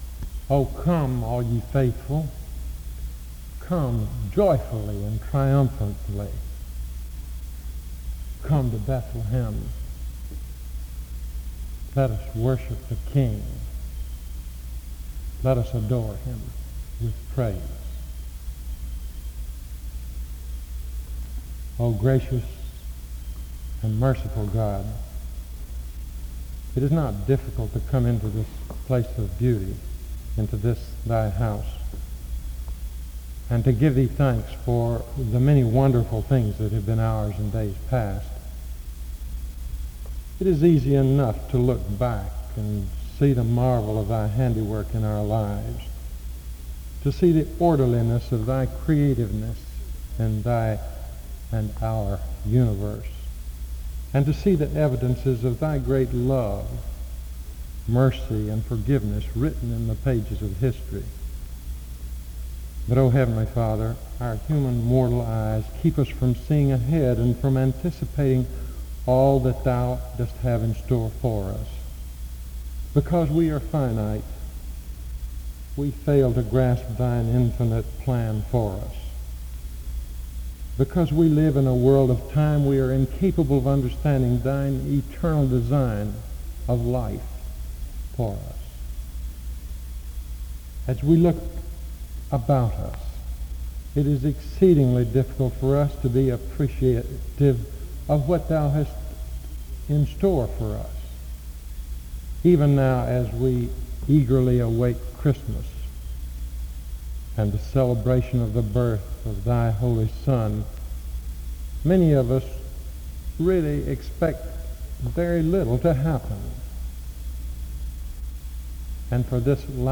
The service starts with a prayer from 0:00-2:44. An introduction to the choir singing is given from 2:47-3:07.
A prayer is offered from 5:10-8:29.
He asks the chapel what they are expecting for this Christmas. Music plays from 24:27-27:50.